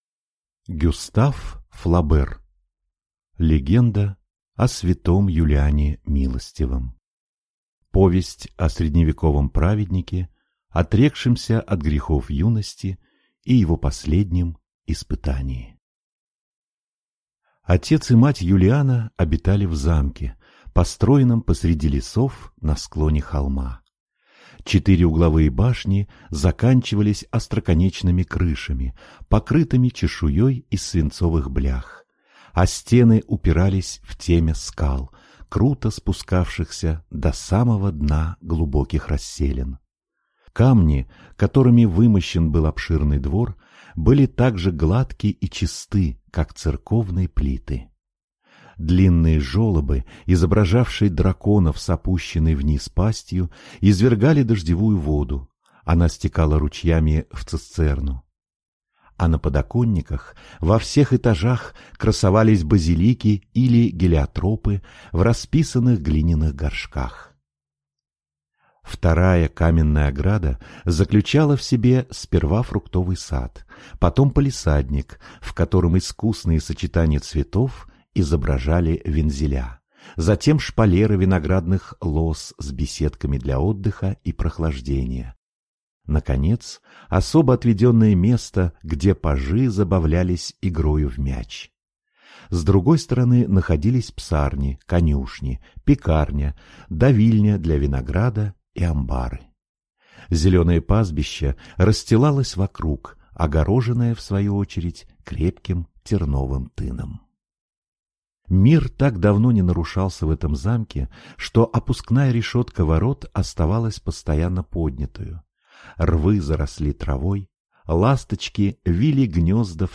Аудиокнига Легенда о св. Юлиане Милостивом | Библиотека аудиокниг